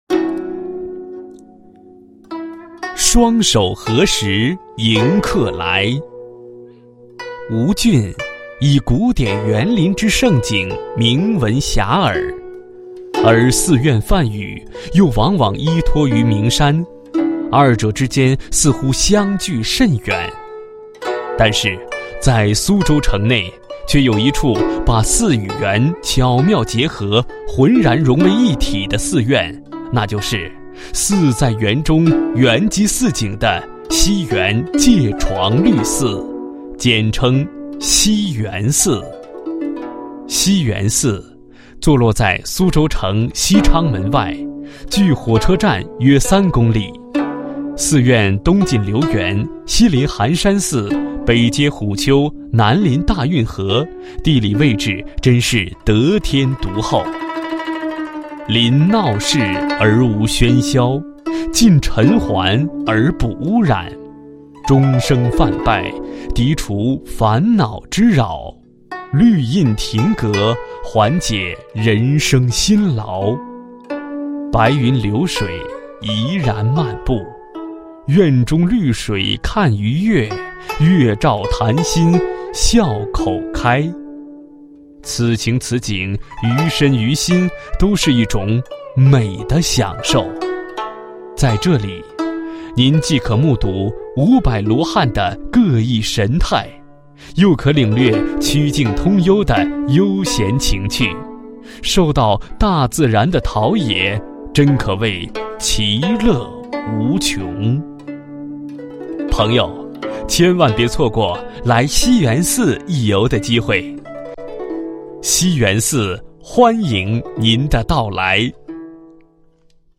欢迎辞